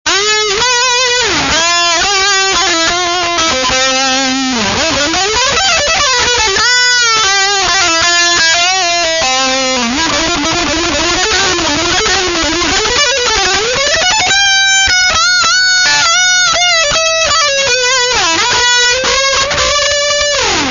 lead guitar.